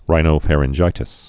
(rīnō-fărĭn-jītĭs)